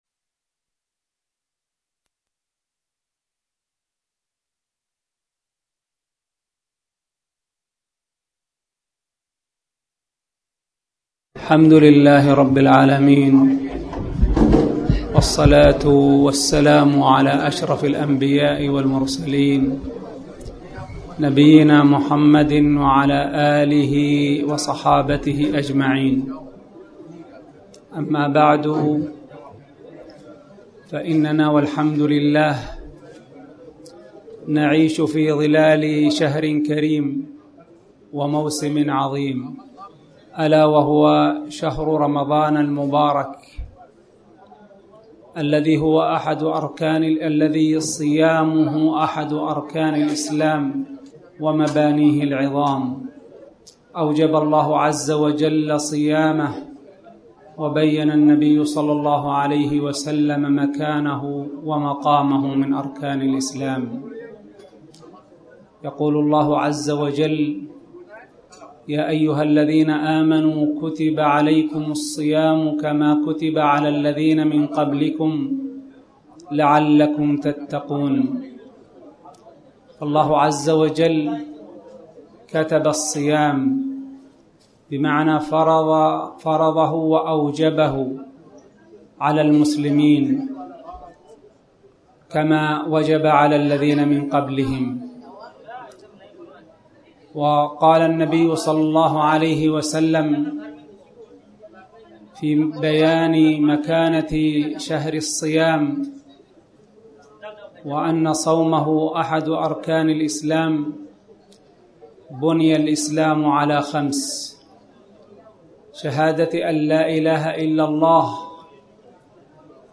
تاريخ النشر ٧ رمضان ١٤٣٩ المكان: المسجد الحرام الشيخ: فضيلة الشيخ عبدالله بن محمد آل خنين فضيلة الشيخ عبدالله بن محمد آل خنين فضل الصيام